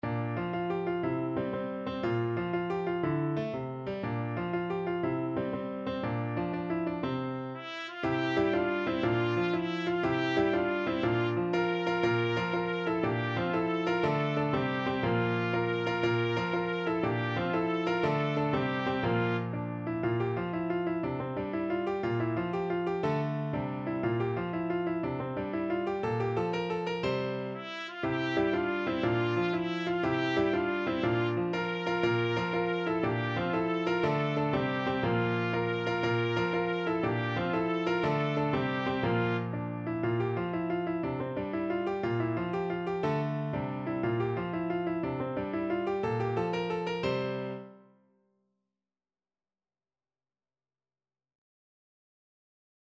Classical Halle, Adam de la J'ai encore une tel paste from Le jeu de Robin et Marion Trumpet version
F major (Sounding Pitch) G major (Trumpet in Bb) (View more F major Music for Trumpet )
With energy .=c.120
6/8 (View more 6/8 Music)
Instrument:
Trumpet  (View more Easy Trumpet Music)
Classical (View more Classical Trumpet Music)